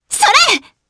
Selene-Vox_Attack3_jp.wav